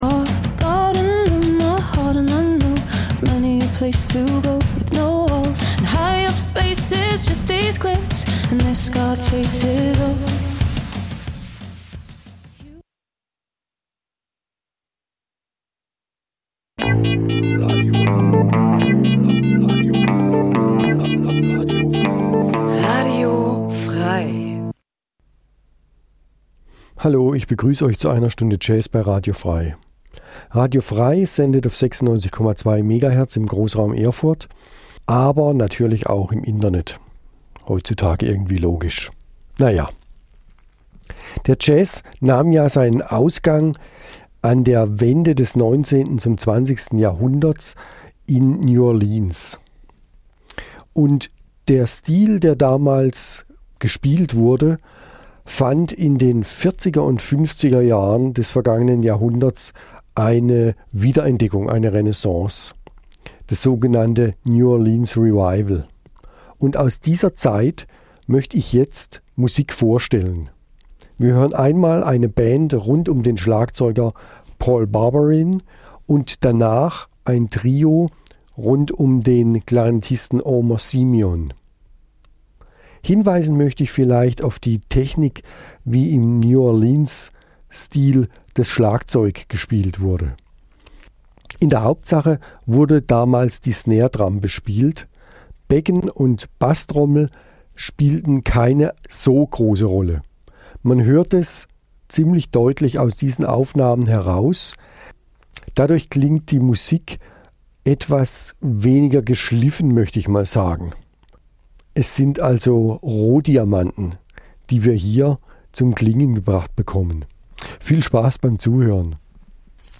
Eine Stunde Jazz